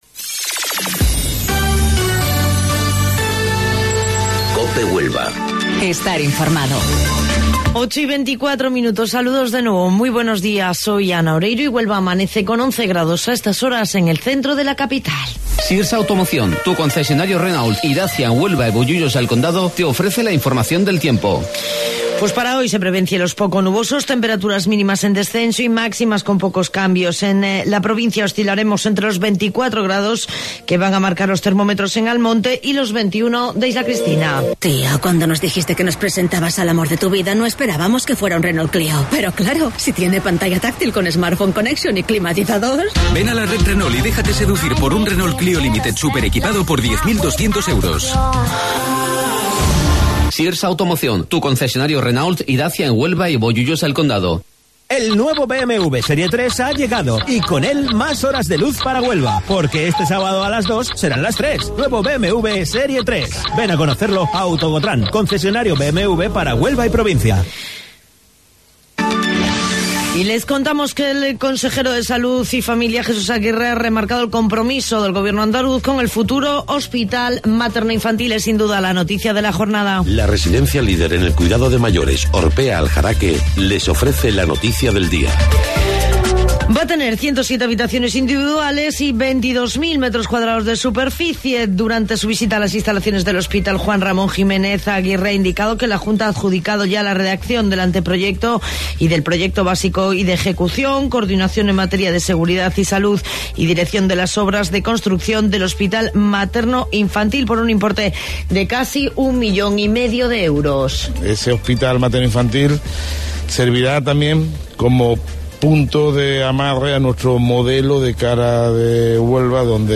AUDIO: Informativo Local 08:25 del 28 de Marzo